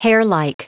Prononciation du mot hairlike en anglais (fichier audio)
Prononciation du mot : hairlike